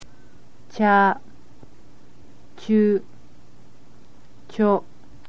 click on any of a group to hear the group spoken